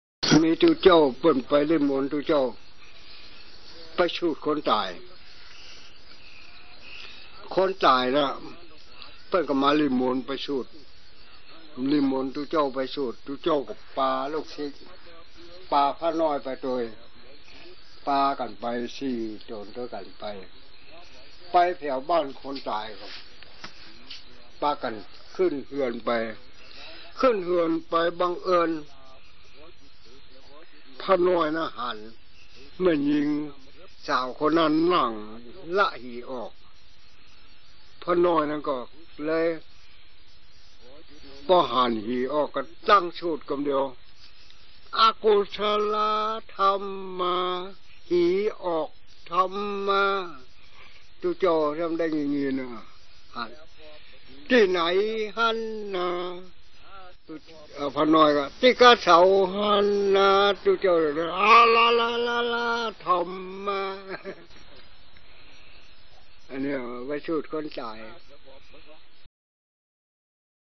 ขอบเขตและสื่อ : เทปรีล